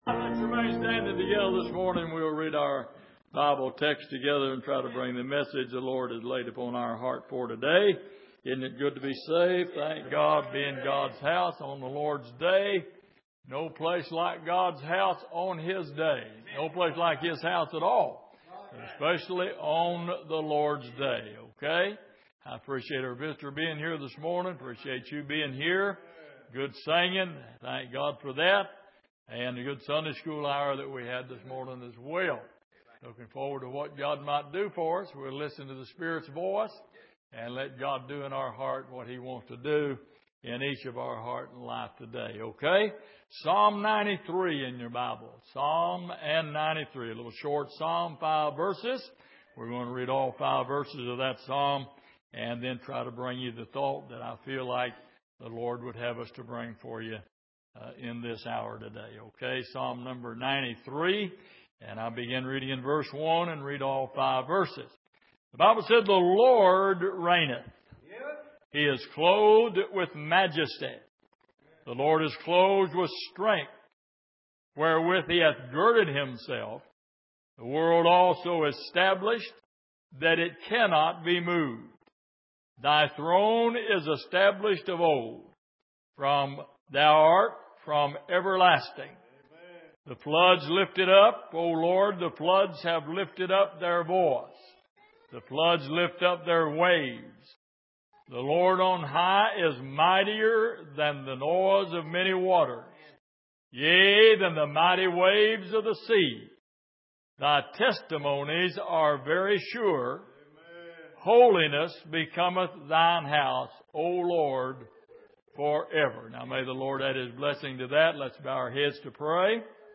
Passage: Psalm 93:1-5 Service: Sunday Morning